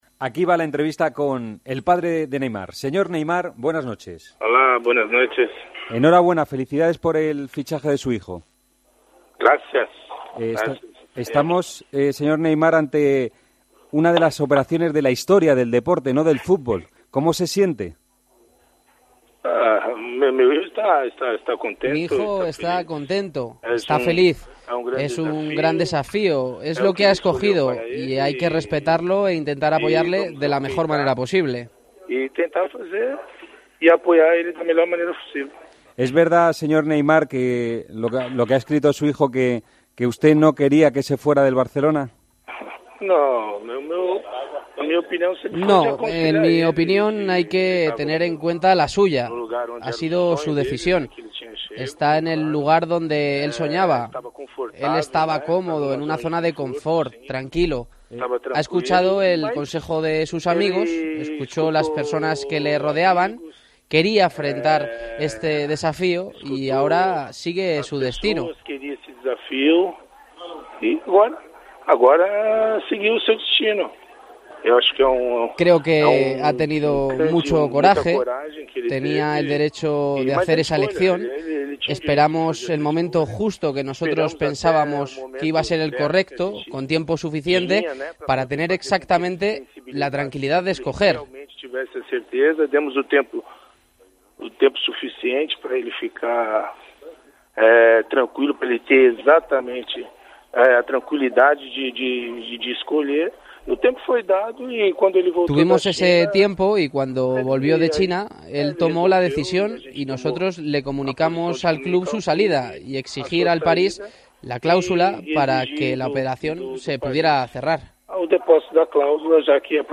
Neymar Santos Senior ha pasado por El Partidazo de COPE tras el fichaje de su hijo por el PSG.